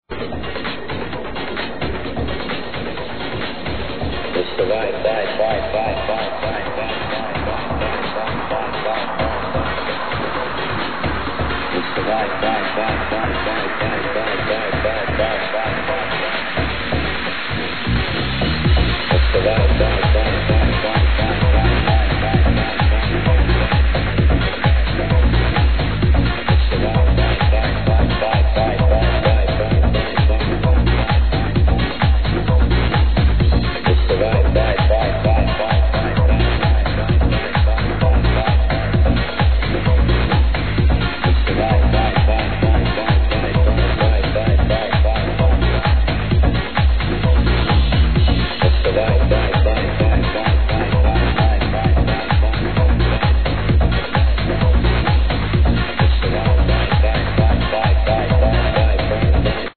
Love is in the air ---- ANYONE know this House tune?